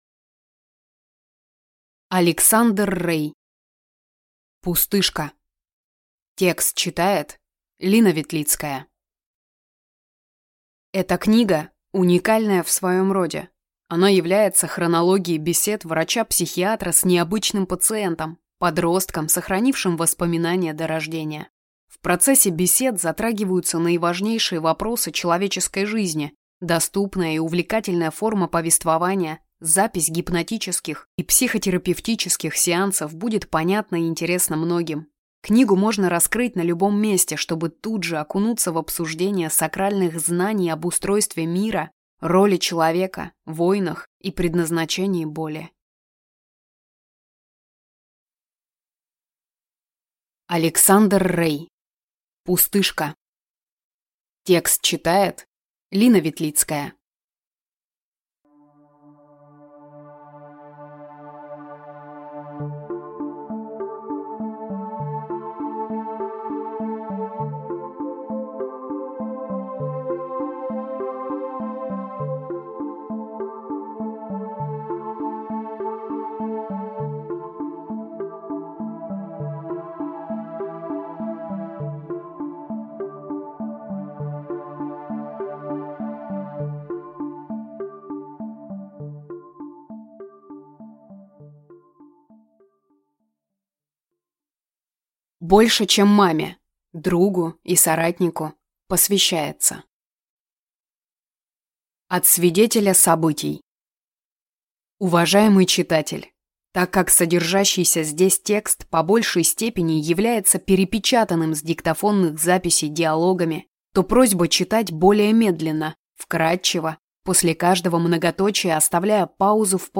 Аудиокнига Пустышка | Библиотека аудиокниг